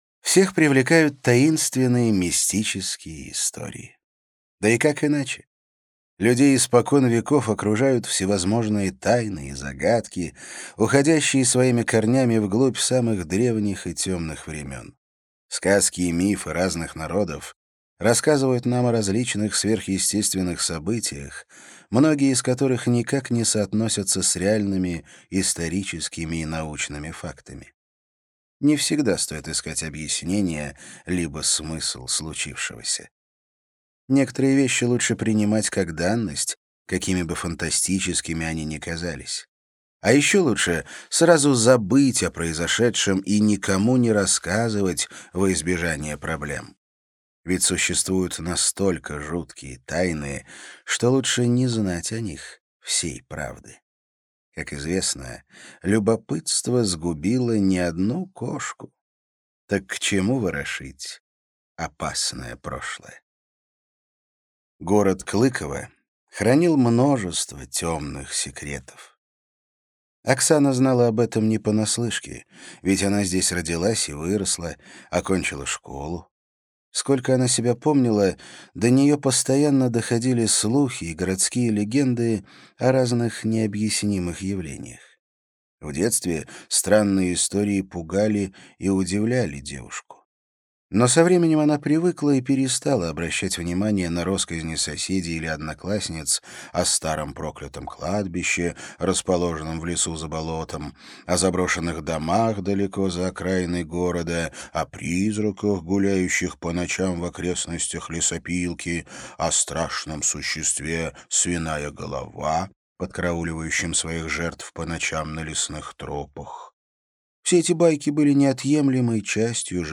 Аудиокнига Пандемониум. Город темных секретов | Библиотека аудиокниг